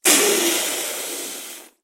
战斗的声音 " 血液喷涌2
描述：由衣架，家用餐具和其他奇怪物品制成的剑声。
标签： 战斗 战争 弗利 战斗 行动
声道立体声